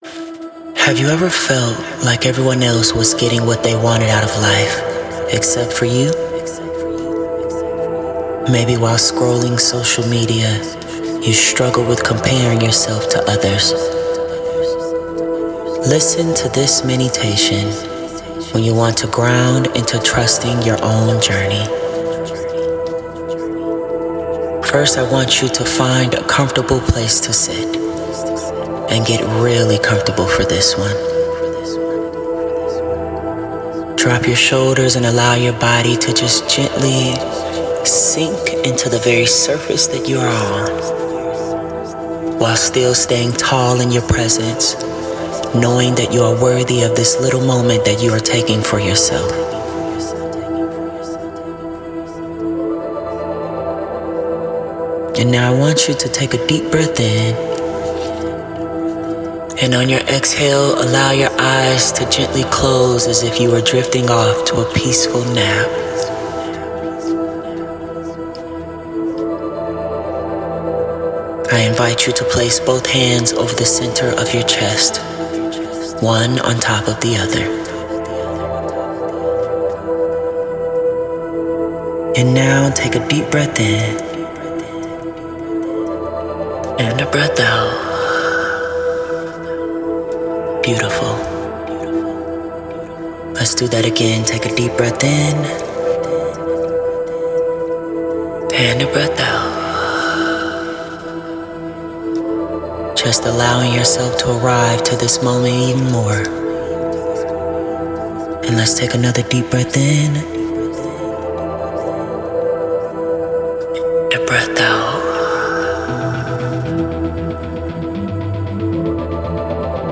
Once recorded, our musicians infuse the mini with unique, powerful music, capturing its essence.